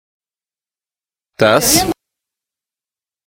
uitspraak Thuis
thuis_mot.mp3